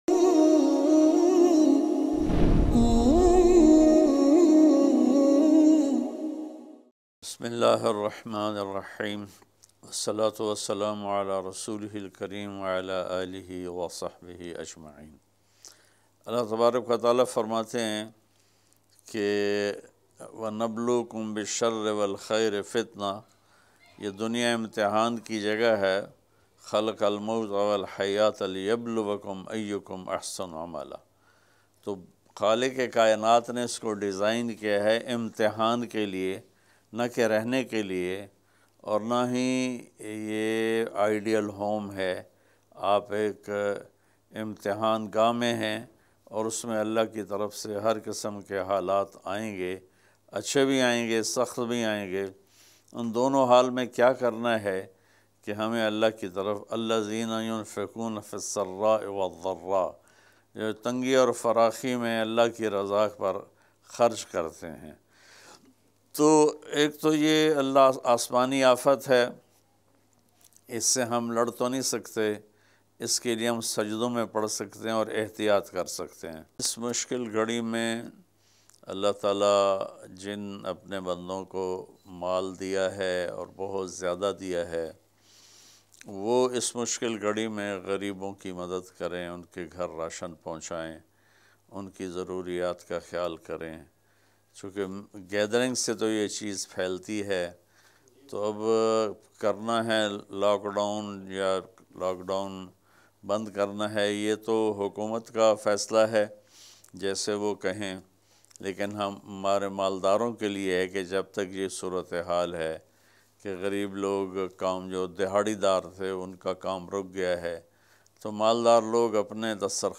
Maldaron Sy Apeel Molana Tariq Jamil Latest Bayan MP3 Download